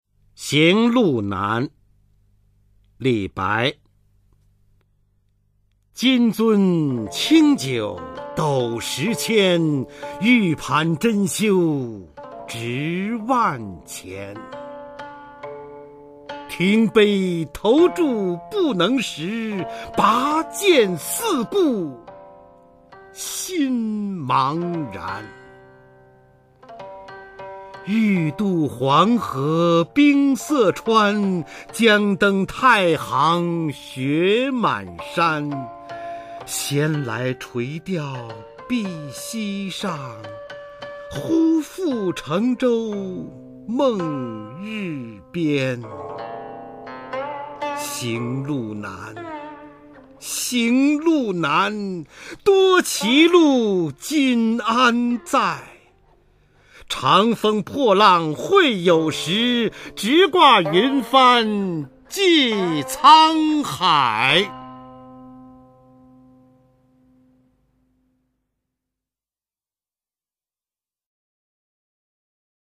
[隋唐诗词诵读]李白-行路难（男） 唐诗吟诵